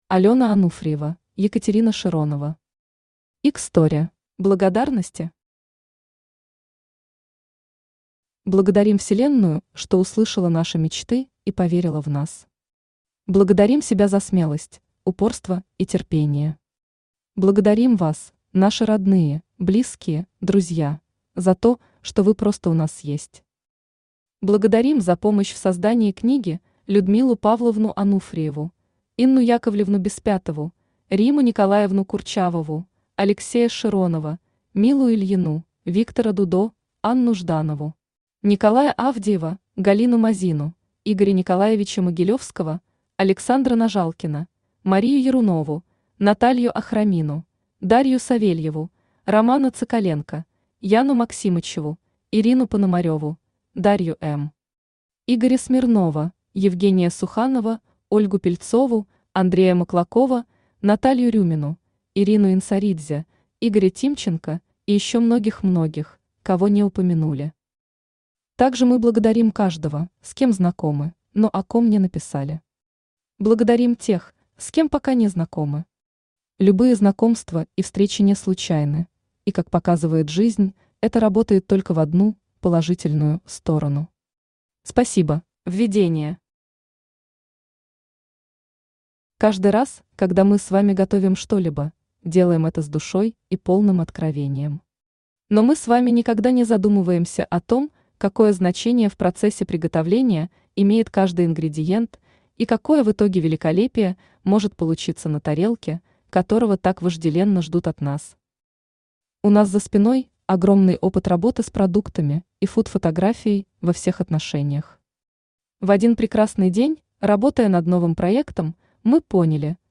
Aудиокнига Egg'story Автор Алена Ануфриева Читает аудиокнигу Авточтец ЛитРес.